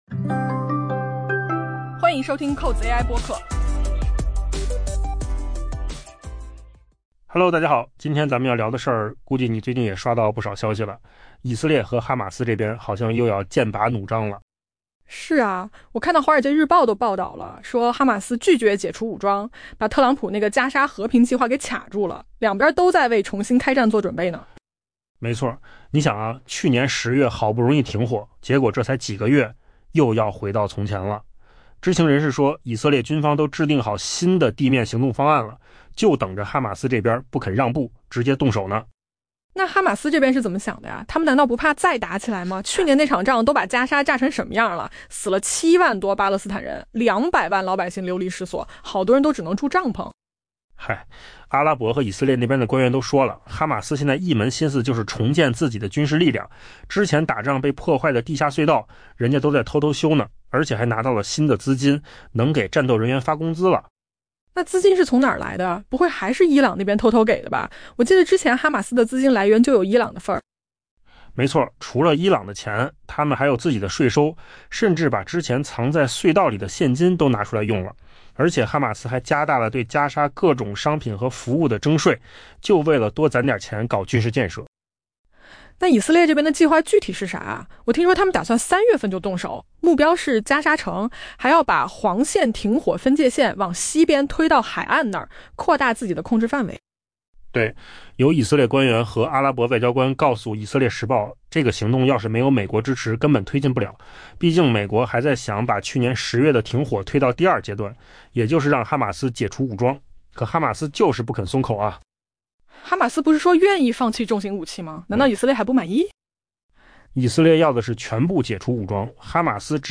AI 播客：换个方式听新闻 下载 mp3 音频由扣子空间生成 据 《华尔街日报》 报道，知情人士透露，以色列与哈马斯正准备重新开战，因该巴勒斯坦武装组织拒绝解除武装——这一要求正阻碍特朗普的加沙和平计划的推进。